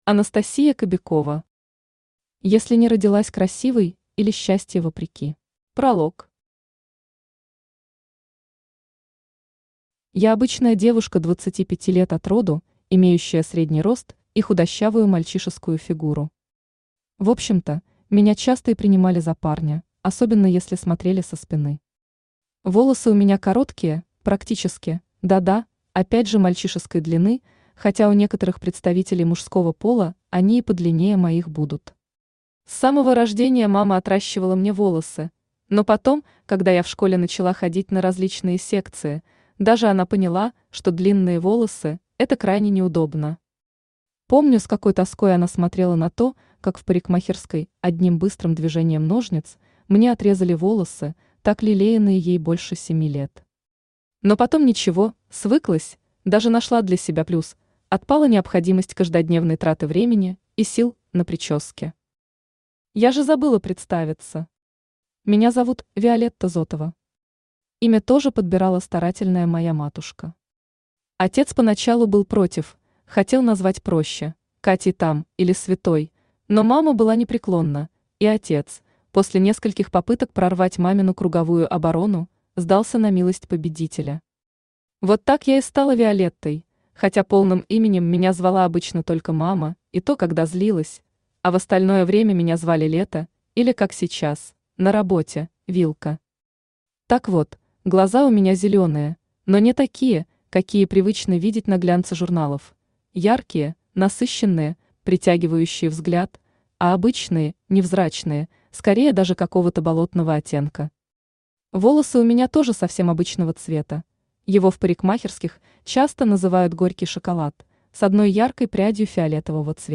Аудиокнига Если не родилась красивой или Счастье вопреки | Библиотека аудиокниг
Aудиокнига Если не родилась красивой или Счастье вопреки Автор Анастасия Кобякова Читает аудиокнигу Авточтец ЛитРес.